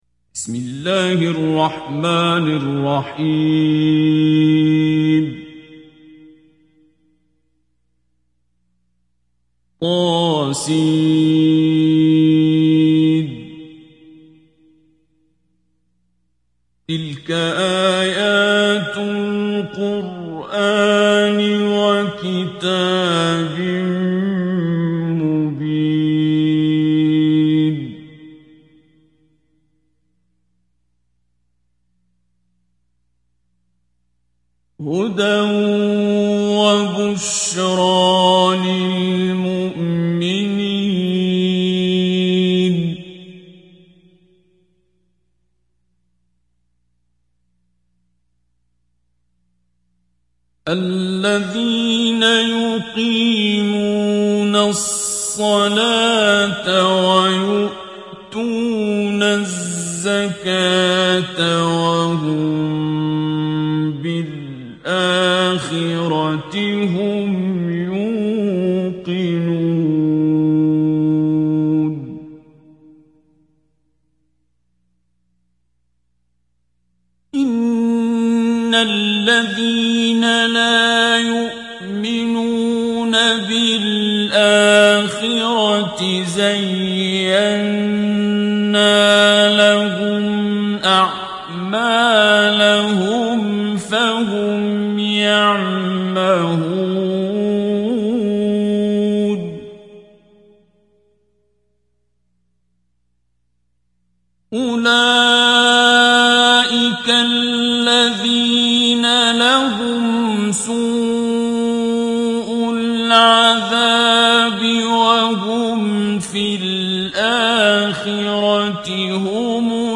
Қуръони карим тиловати, Қорилар.